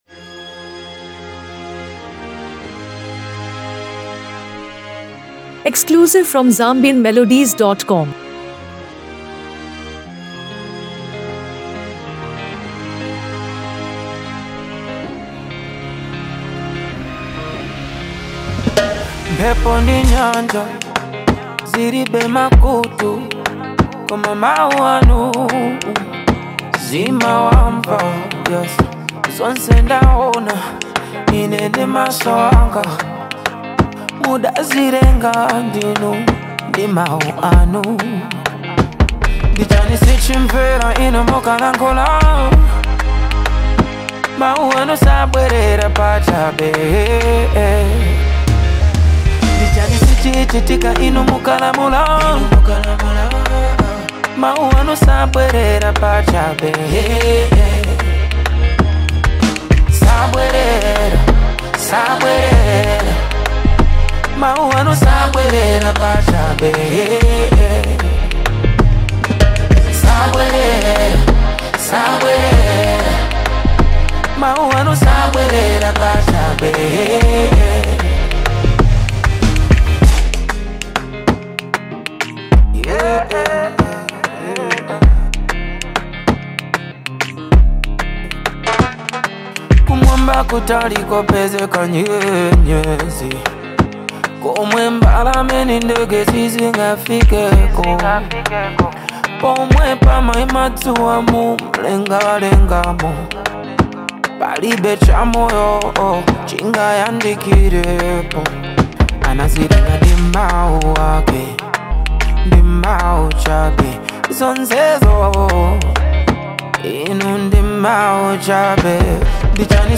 soulful hit